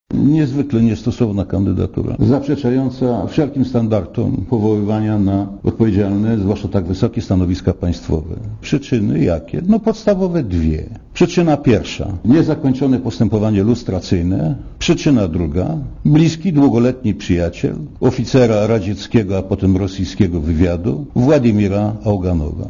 Dla Radia Zet mówi Andrzej Milczanowski (88 KB)